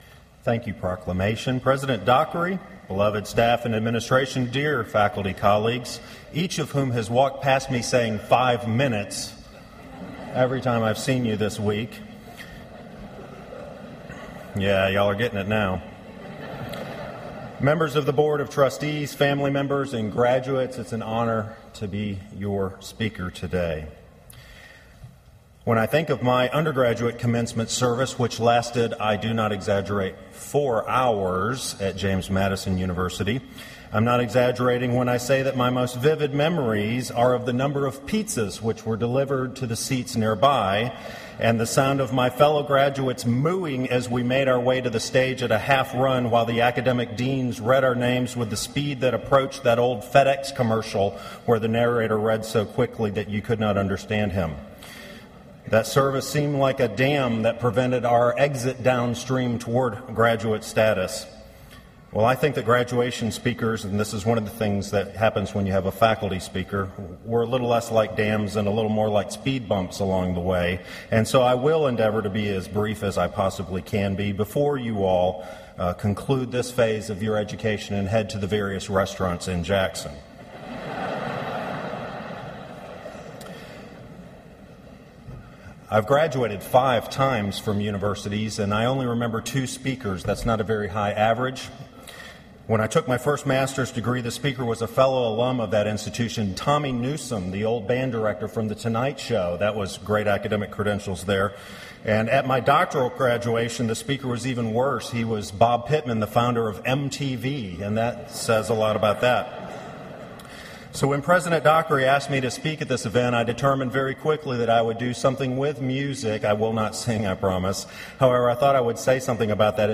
Fall Graduation
Union University Address: Living with a Musical Heart Recording Date